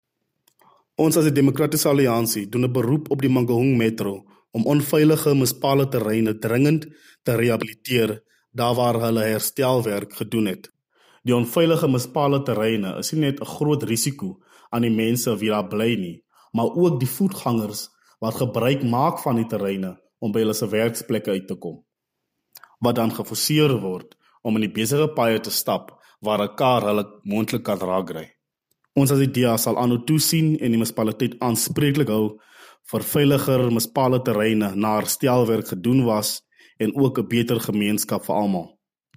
Afrikaans soundbites by Cllr Lyle Bouwer and